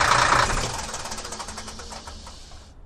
Truck, Hino Diesel, Shut Off